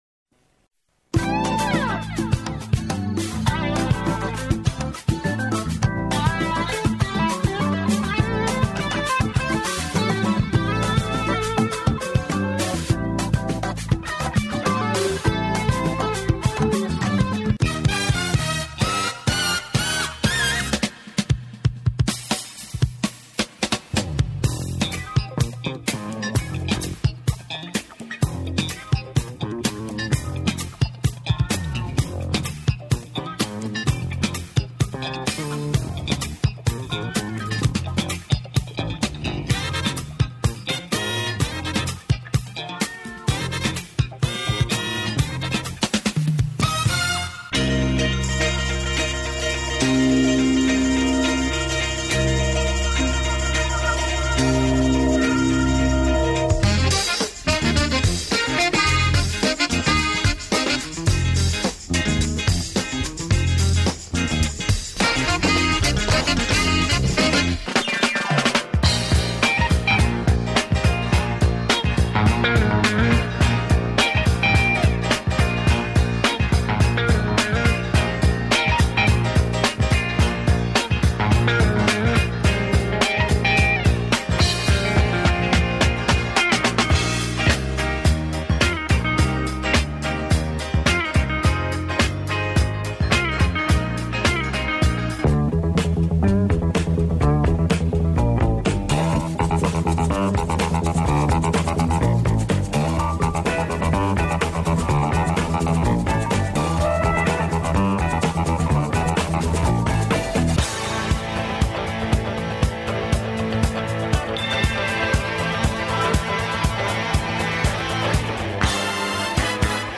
INSTRUMENTAL-UNTERLEGER  (ÜBERBLICK)